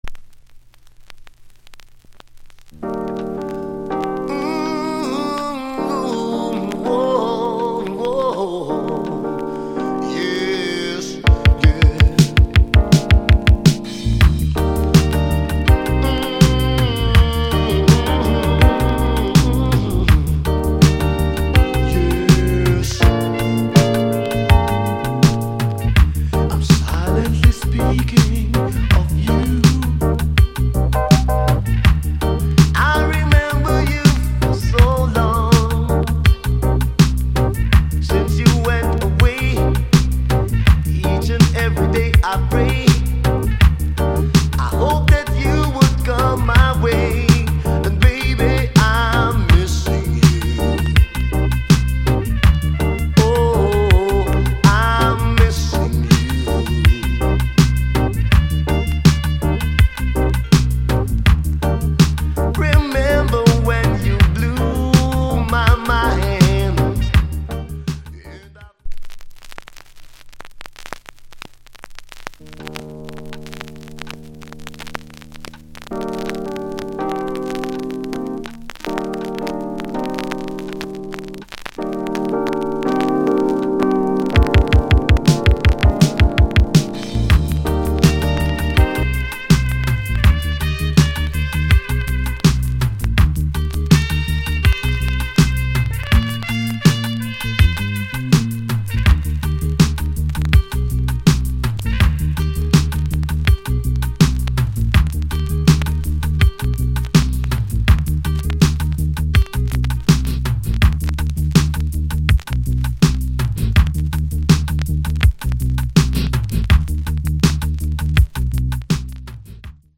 ラヴァーズ・ロック・テイストのナンバー。 胸を締め付けられるかのように、哀愁たっぷりに歌い上げる